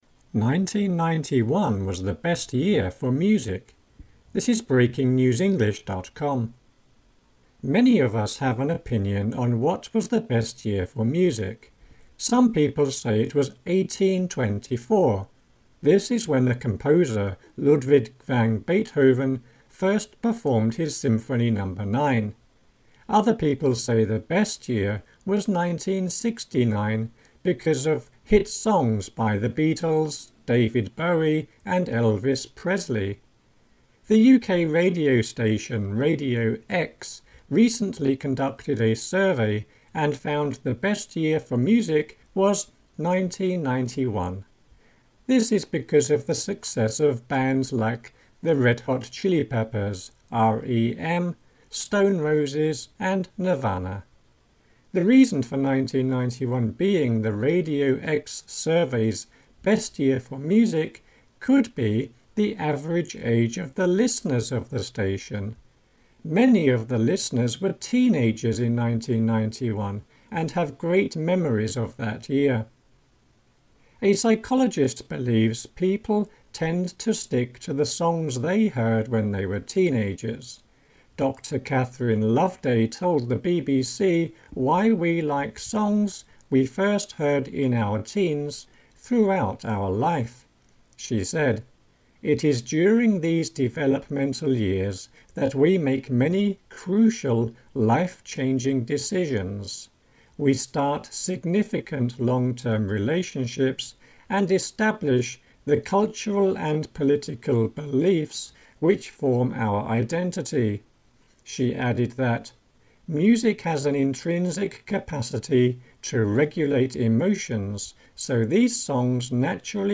AUDIO(Slow)